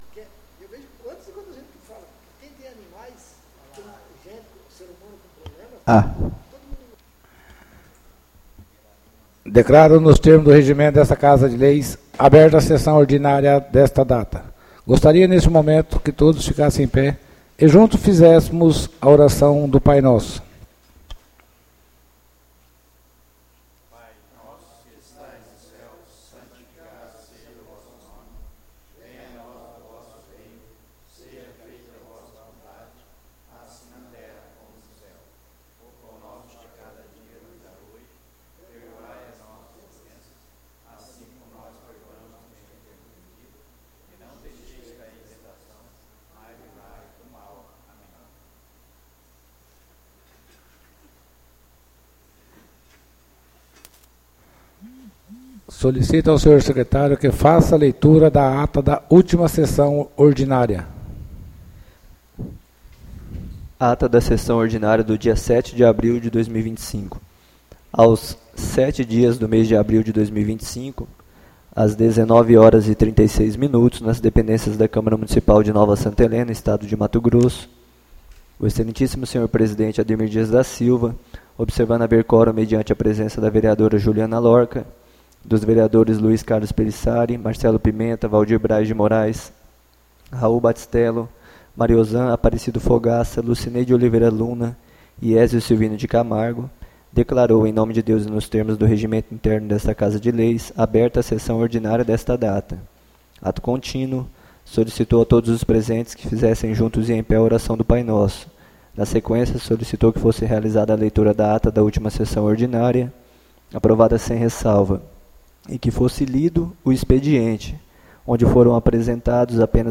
ÁUDIO SESSÃO 14-04-25 — CÂMARA MUNICIPAL DE NOVA SANTA HELENA - MT